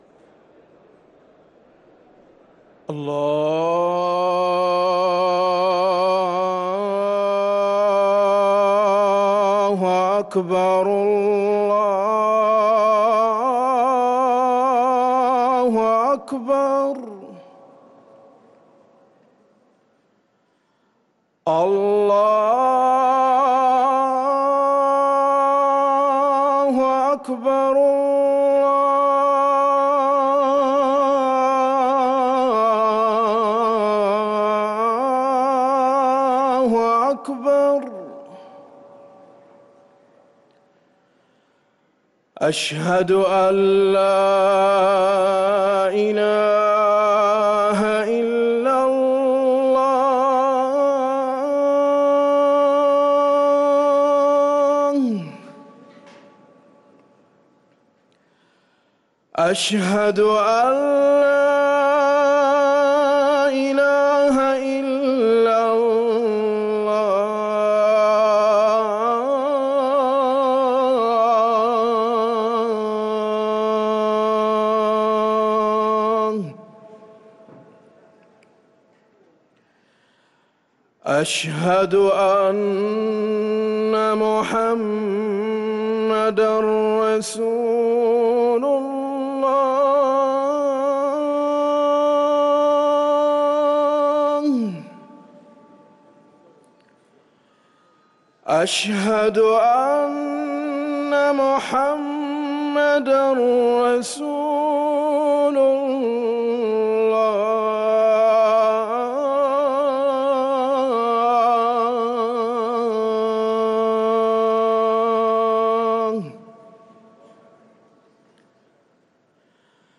أذان العصر للمؤذن